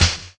Raw Snare F Key 27.wav
Royality free acoustic snare tuned to the F note. Loudest frequency: 2670Hz
raw-snare-f-key-27-3hJ.mp3